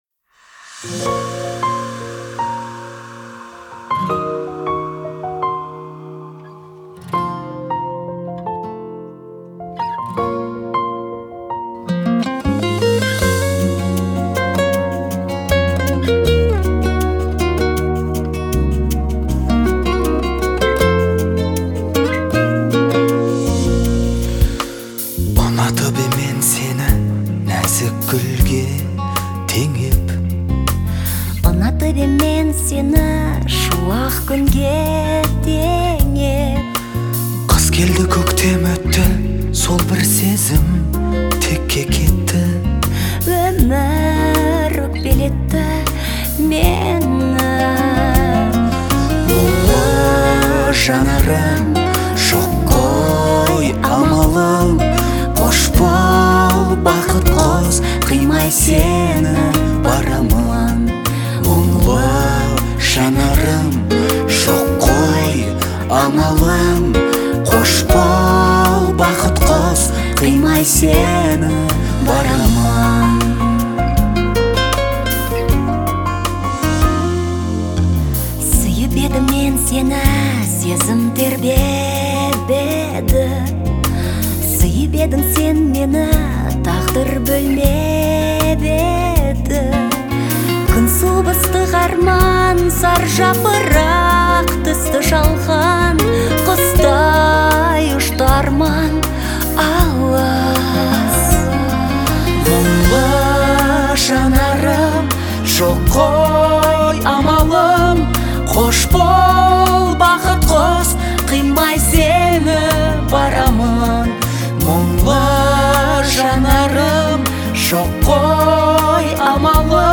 это трогательный дуэт в жанре казахской поп-музыки